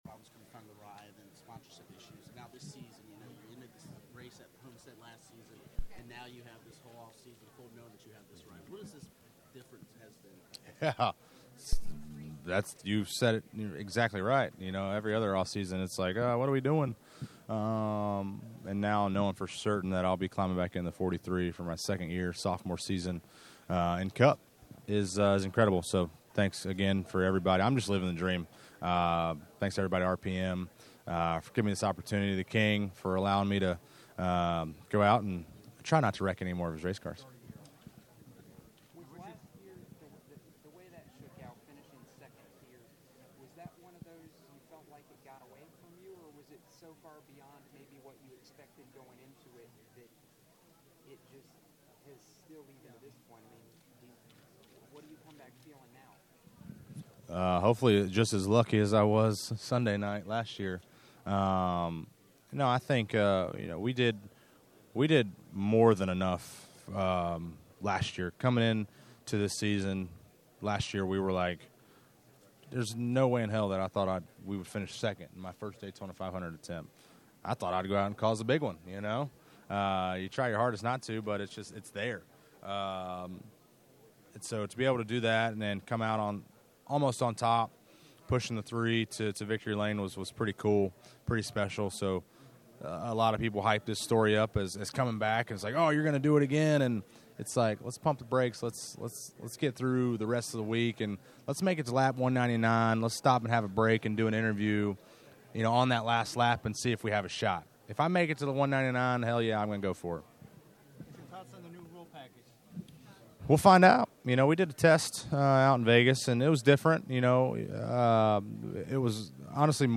NASCAR driver Bubba Wallace speaks with the media during the Monster Energy NASCAR Cup Series 61st Annual Daytona 500 Media Day at Daytona International Speedway on February 13, 2019 in Daytona Beach, Florida.